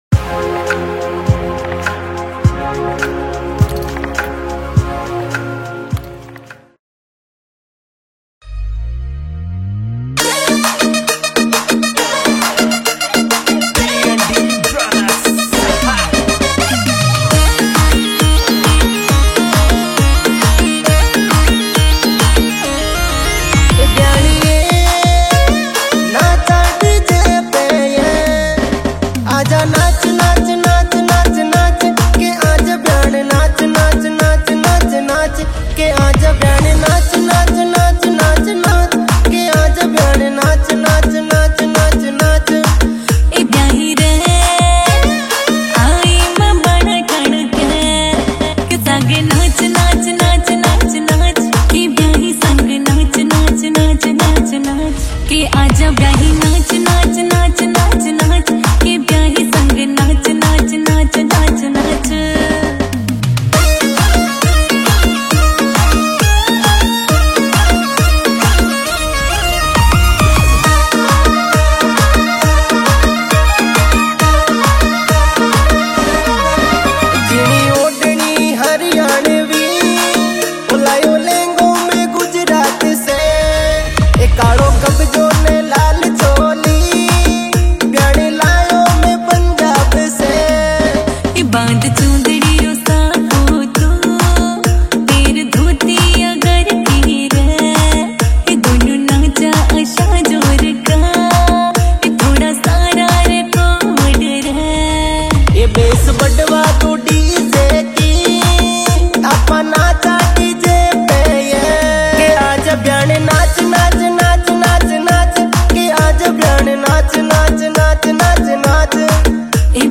Category: Rajasthani